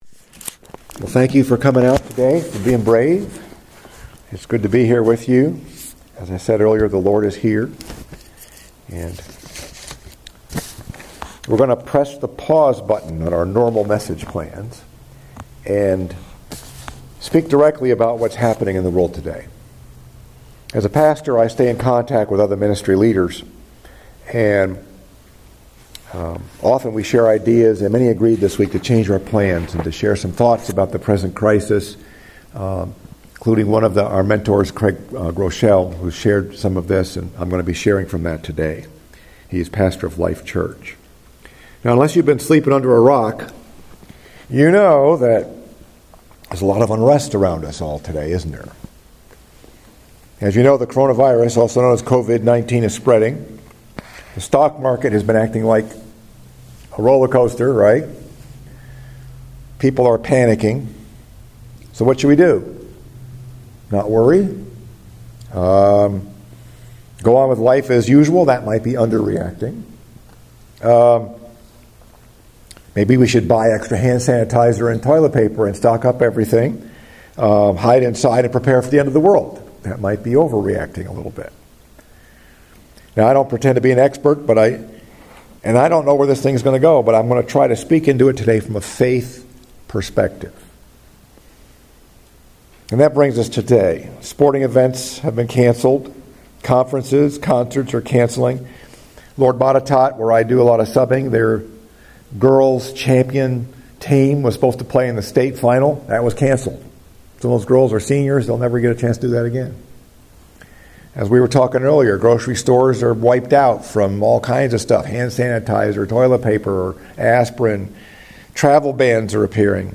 Message: “Not Afraid” Scripture: Various Selections